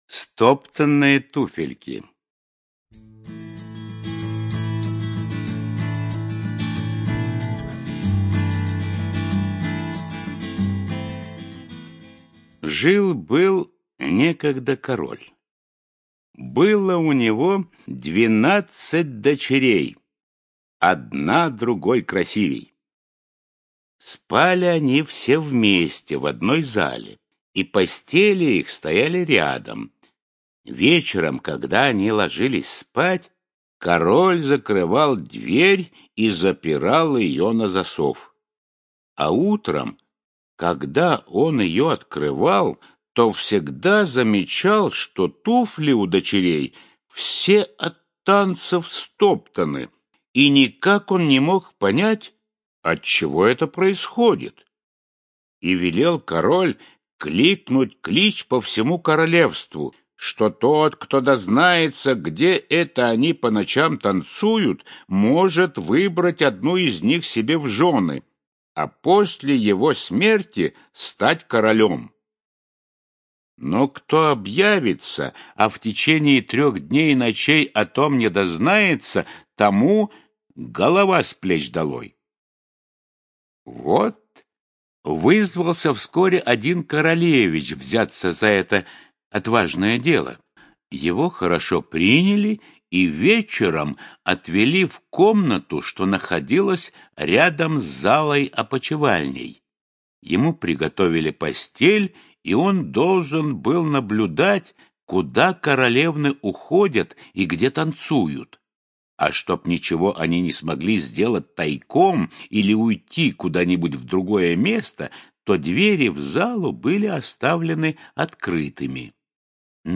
Стоптанные туфельки - аудиосказка братьев Гримм.